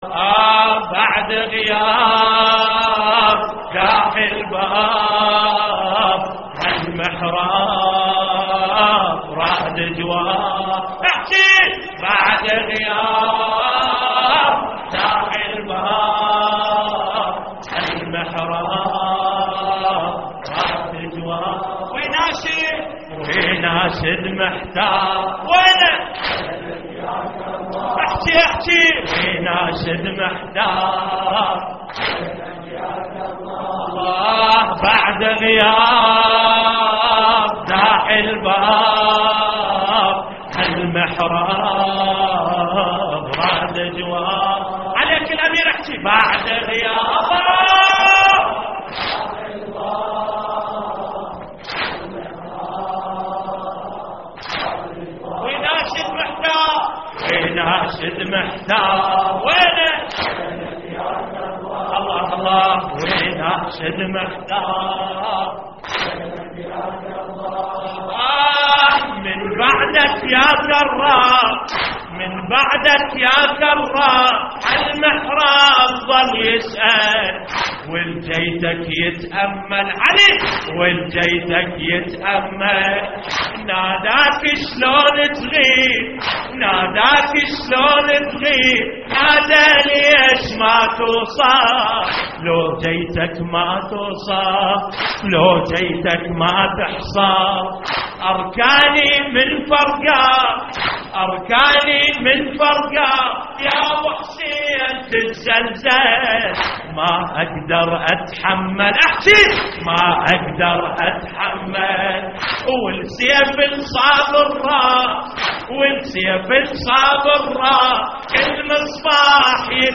تحميل : بعد غياب داحي الباب هالمحراب / مجموعة من الرواديد / اللطميات الحسينية / موقع يا حسين